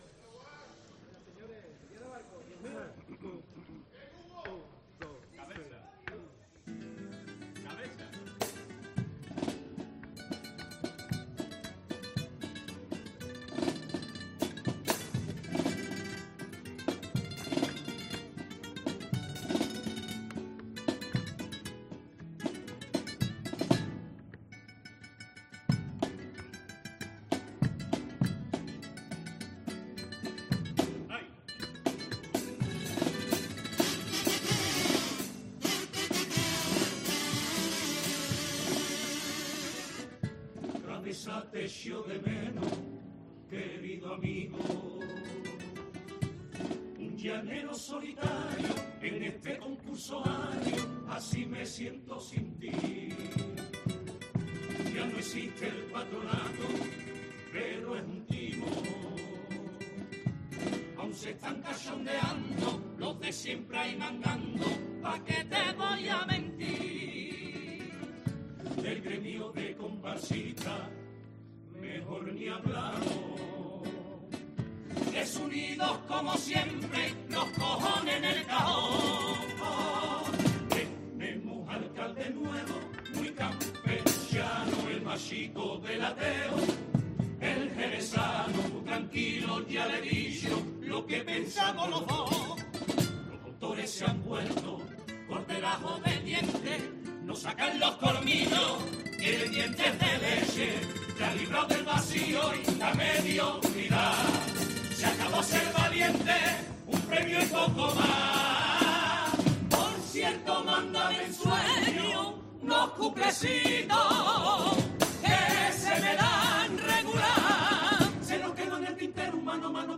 La comparsa 'La oveja negra' interpreta un pasodoble a modo de carta para recordar al Capitán Veneno
Su comparsa, La oveja negra, ha dejado un pasodoble en la última sesión que se ha convertido en un homenaje a Juan Carlos Aragón, fallecido en 2019.